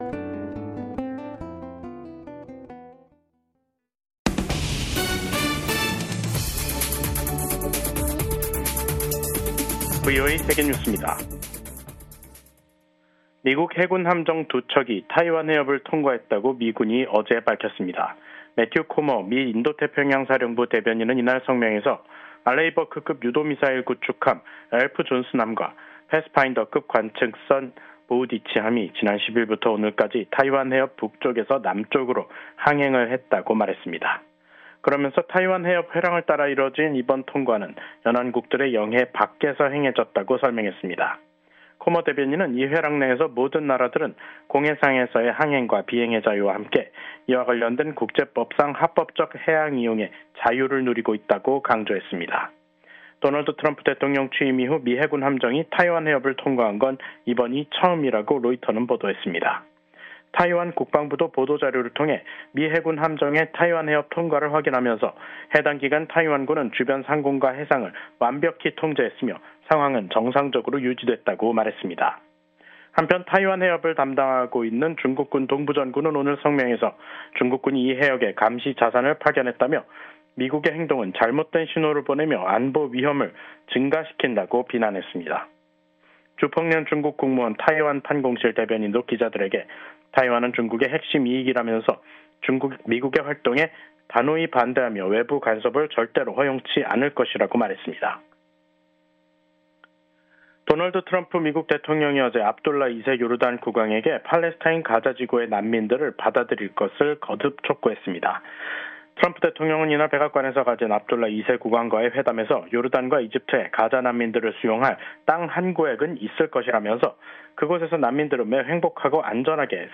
VOA 한국어 간판 뉴스 프로그램 '뉴스 투데이', 2025년 2월 12일 2부 방송입니다. 도널드 트럼프 미국 대통령이 또 다시 김정은 북한 국무위원장과의 정상회담을 추진할 것임을 시사했습니다. 미국인 여성이 북한 정보기술(IT) 인력의 위장 취업을 도운 혐의를 인정했다고 미 법무부가 밝혔습니다. 미국 연방수사국(FBI)이 공개 수배 중인 대북제재 위반자가 최근 급증 양상을 보이고 있습니다.